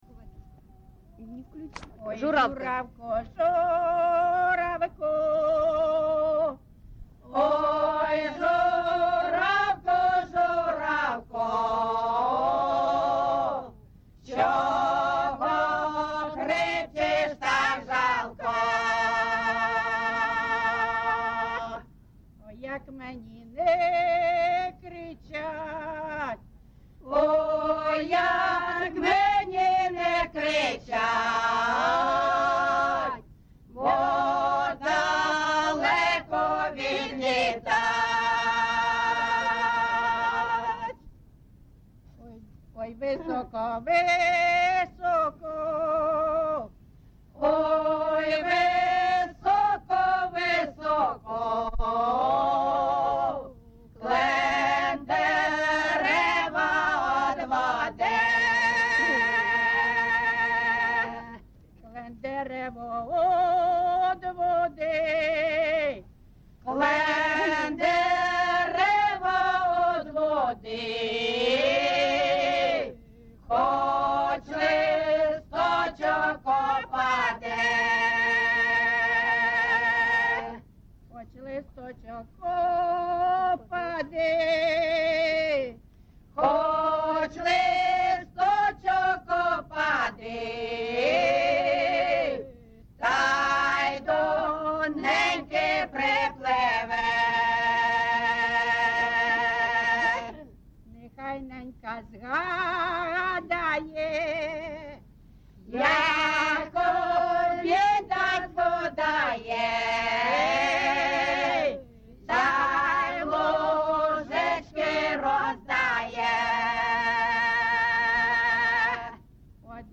ЖанрПісні з особистого та родинного життя, Строкові
Місце записус. Іскра (Андріївка-Клевцове), Великоновосілківський (Волноваський) район, Донецька обл., Україна, Слобожанщина